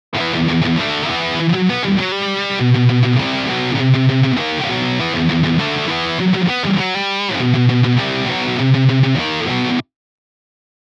ein paar custom-gitamps von helix-native (erster teil: ampname, 2.teil: cabname):
habs jetzt mal in mono gesplittet und nur eine datei davon geampt.